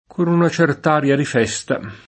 certo [©$rto] agg. e avv. — come agg., elis. in alcuni casi: con una cert’aria di festa [